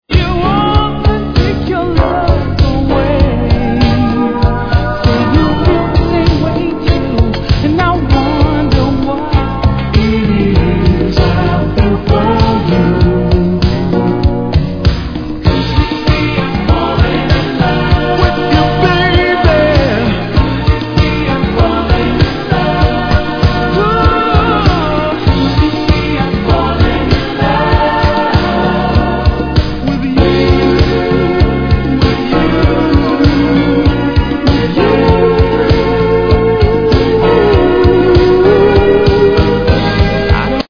Mix CD等にも収録される超キモチイイ1曲!!
Tag       OTHER Soul